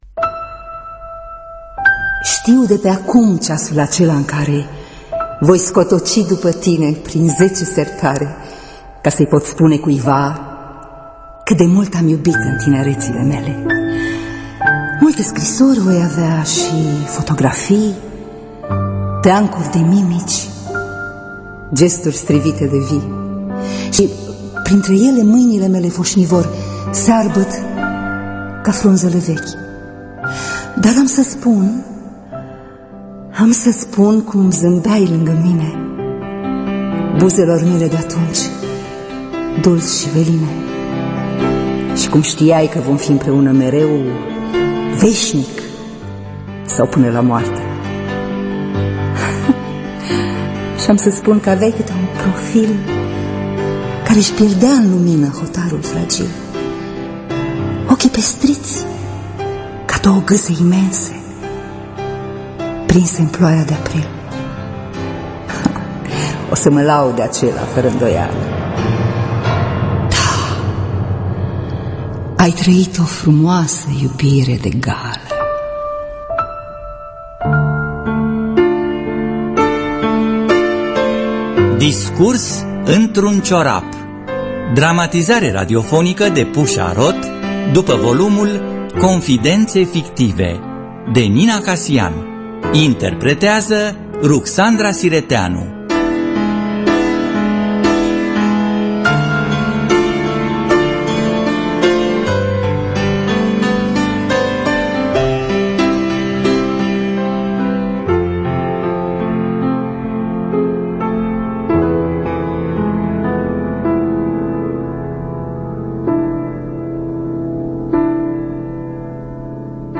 Interpretează: Ruxandra Sireteanu.